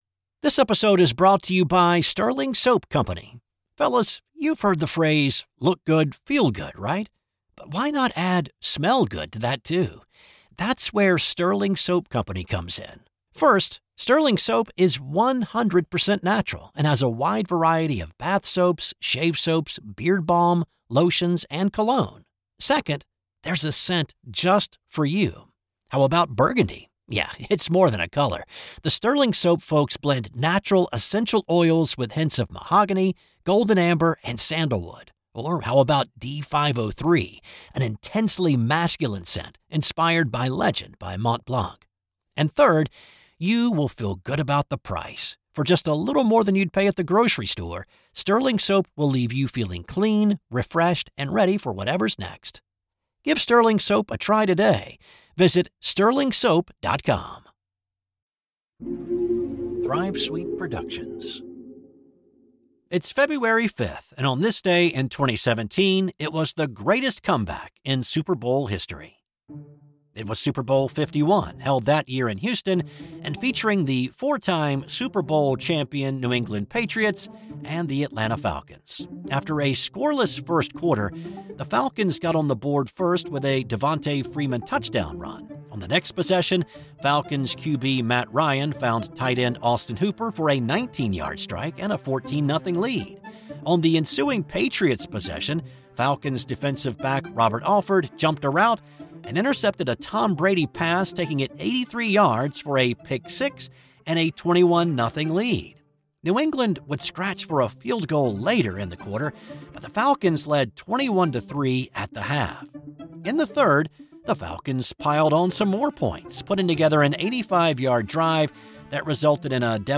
'This Day in Sports History' is a one person operation.